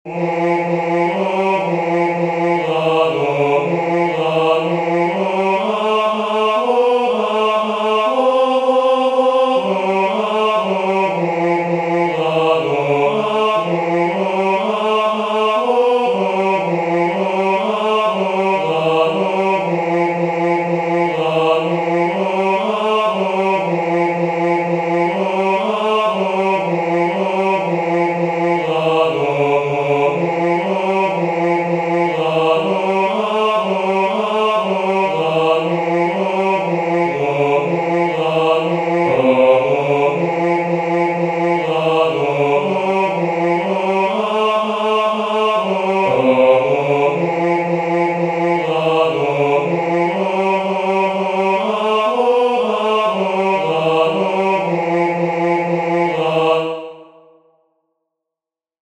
"Vidi conjunctos viros," the first responsory from the second nocturn of Matins, Common of Apostles